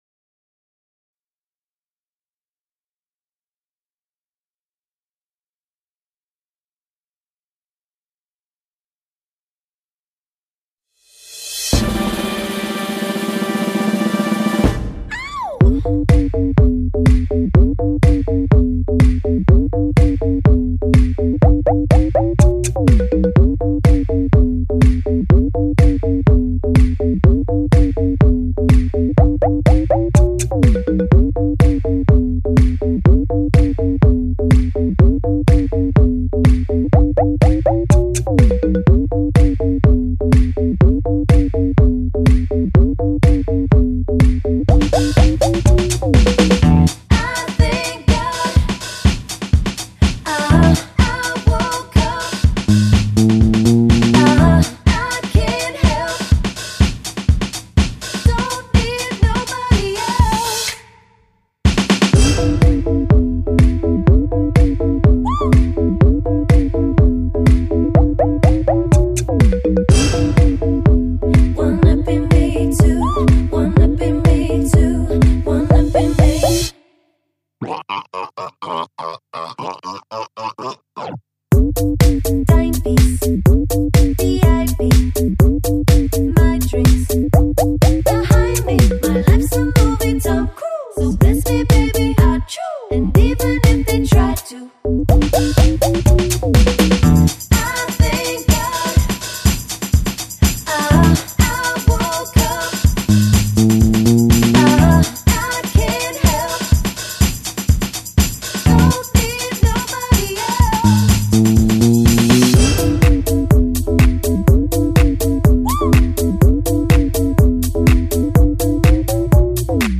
karaoke songs